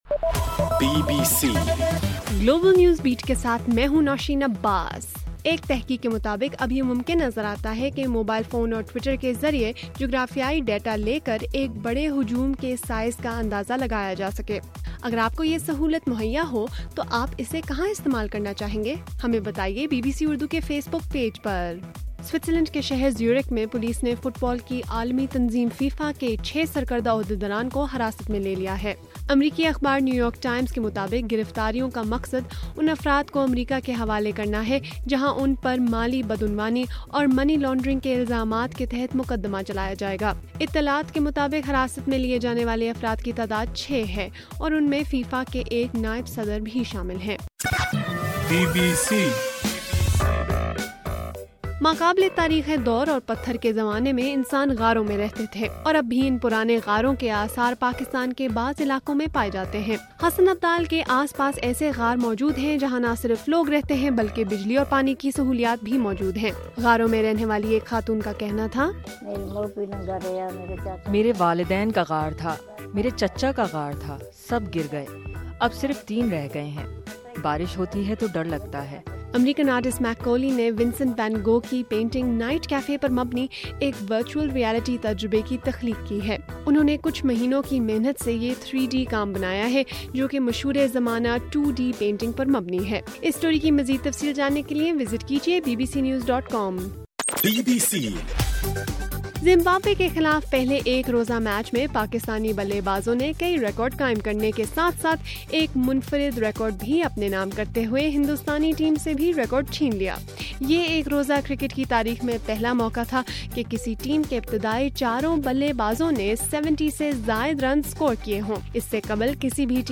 مئی 27: رات 9 بجے کا گلوبل نیوز بیٹ بُلیٹن